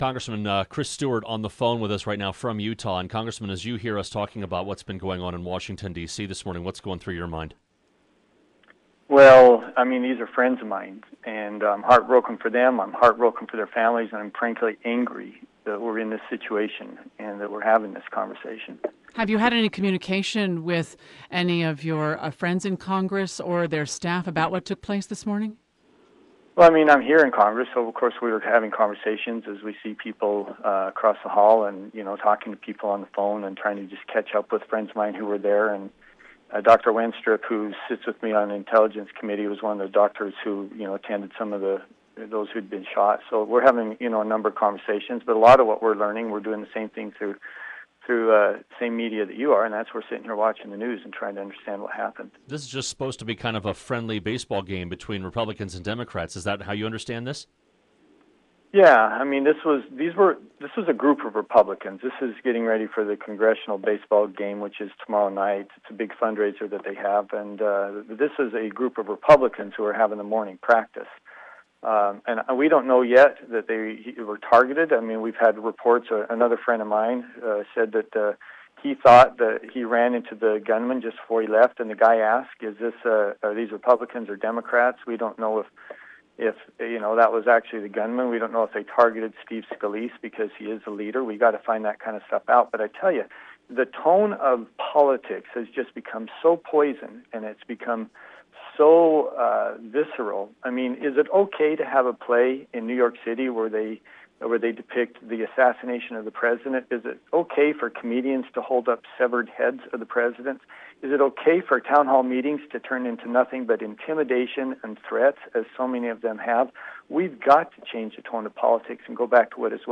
Interview: Representative Chris Stewart reacts to VA shootings